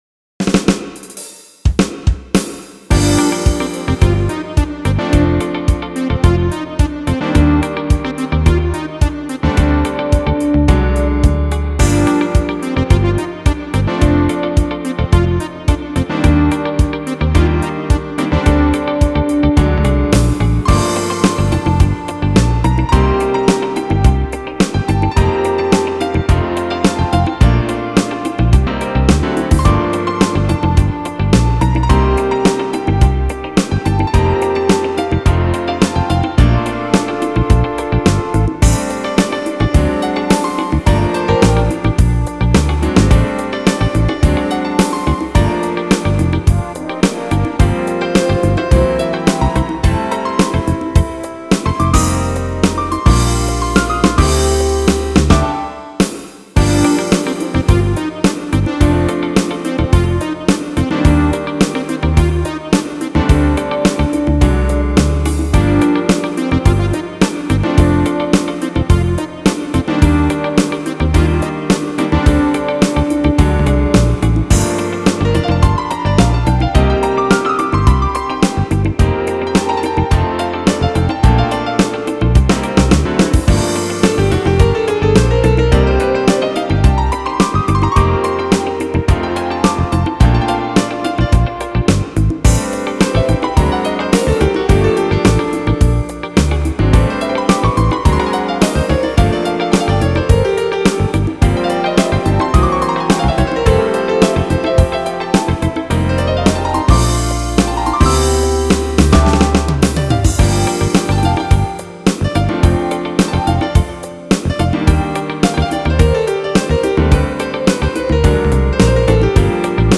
jazz tune